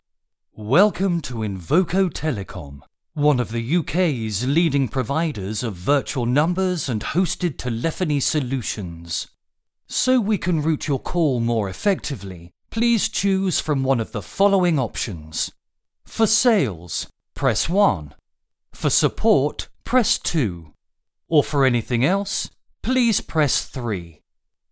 IVR Voiceover – Male M1
Male voiceover – M1 – Up to 120 words professionally recorded.
Professionally recorded voiceover for IVR’s, welcome greetings, system voicemails, etc., for up to 120 words.